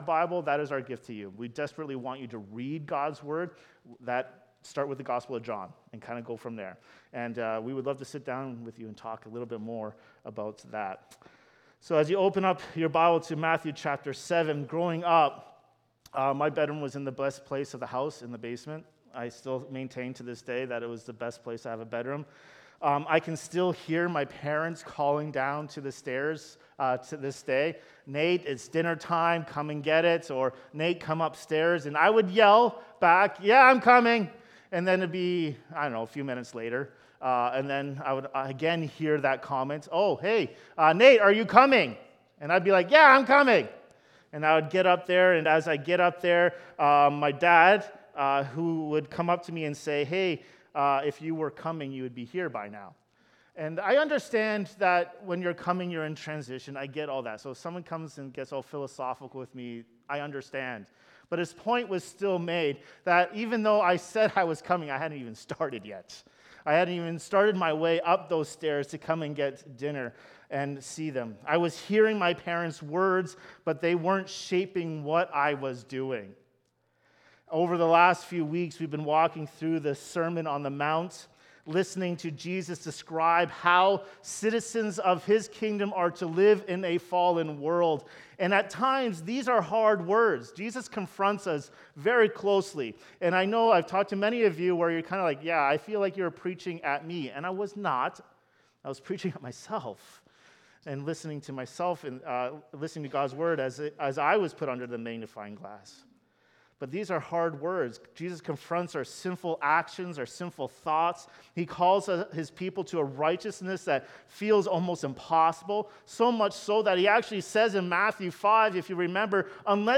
This sermon challenges us to examine whether we are merely listening—or truly following.